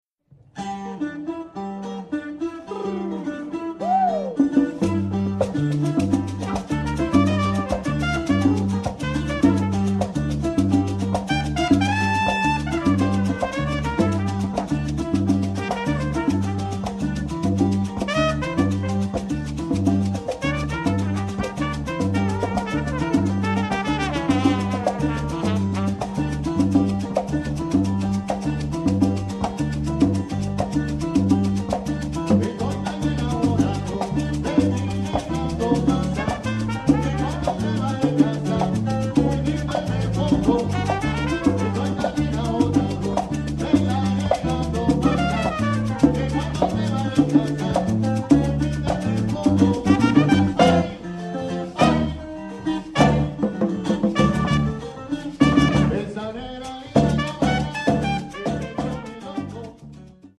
This Seattle Latin Band is a 5-piece group consisting of
tres guitar & coro
lead vocals, maracas and clave
trumpet, coro and clave.